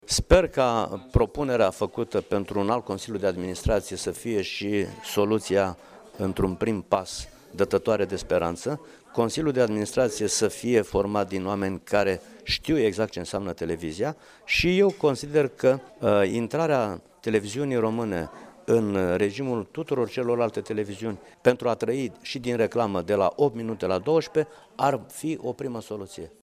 Din Consiliul de Administraţie al Televiziunii Române ar trebui să facă parte profesionişti, chiar dacă sunt sprijiniţi din punct de vedere politic şi asta nu înseamnă modificarea actualei legi, a declarat astăzi, într-o conferinţă de presă, copreşedintele filialei judeţene Iaşi a PNL, senatorul Dumitru Oprea.